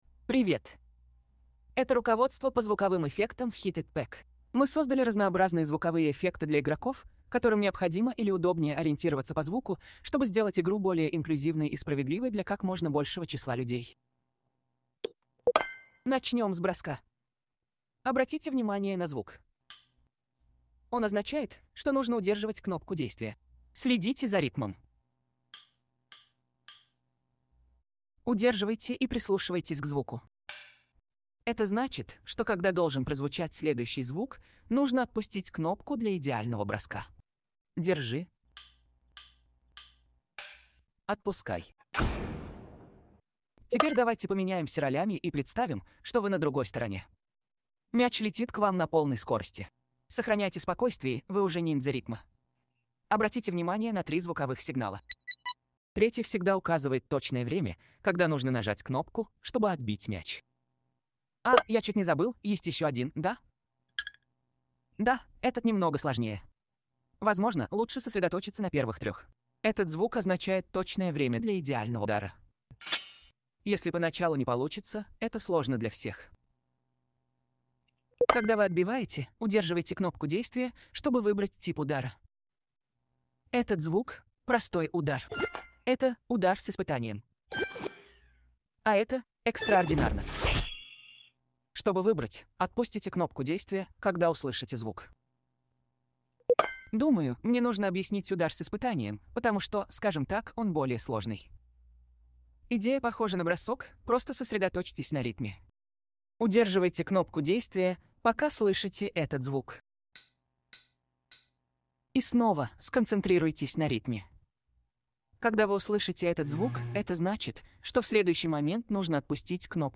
Но ниже я выложу переведенную транскрибацию обучения, и переозвученный на русский язык гайд по звукам специальных возможностей.
Аудиогайд по звукам специальных возможностей.